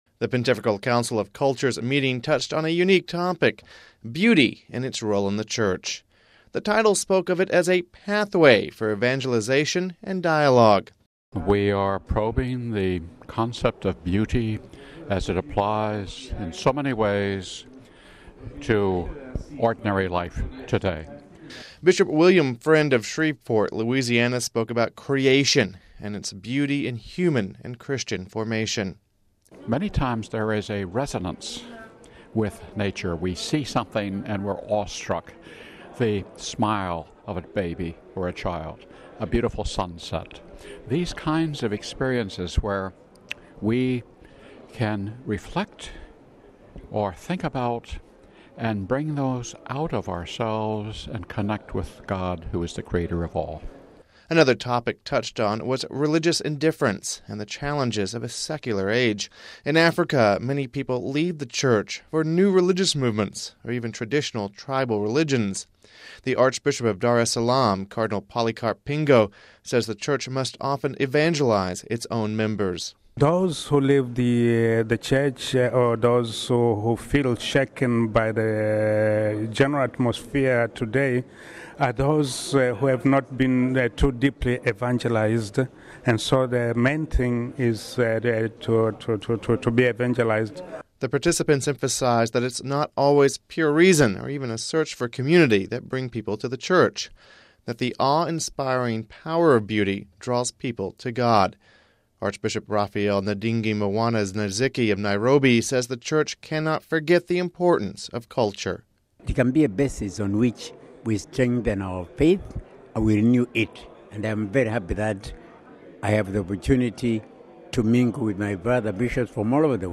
reports...